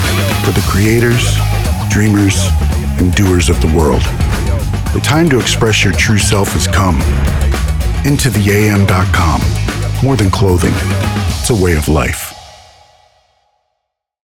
COMMERCIAL
Into The AM Apparel - Edgy & Fashion-Forward Voiceover